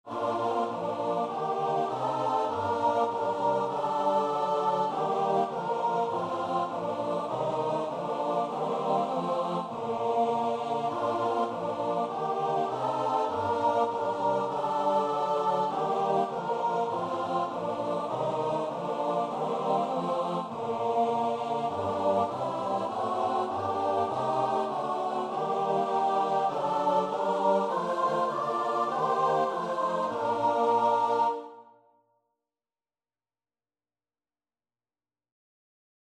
4/4 (View more 4/4 Music)
Choir  (View more Easy Choir Music)
Christian (View more Christian Choir Music)